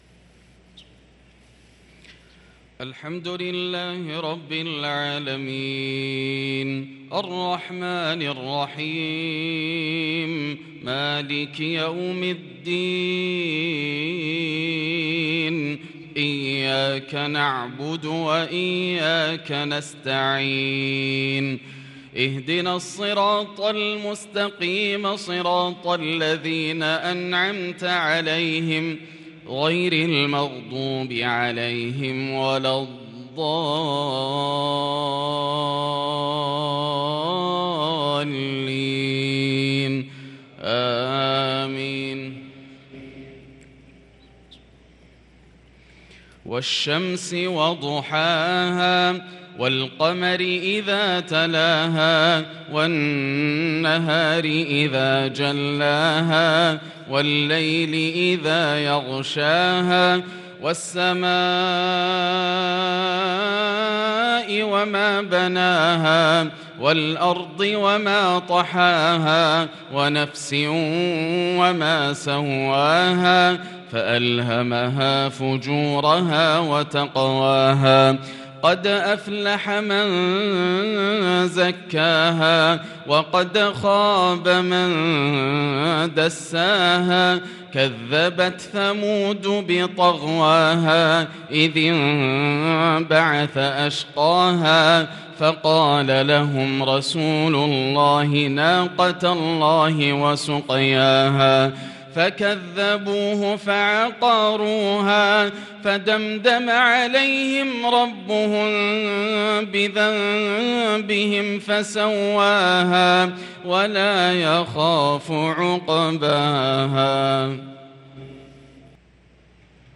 صلاة العشاء للقارئ ياسر الدوسري 14 ربيع الأول 1444 هـ
تِلَاوَات الْحَرَمَيْن .